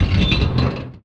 metal_scrape_deep_grind_squeak_01.wav